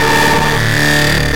amsSiren.ogg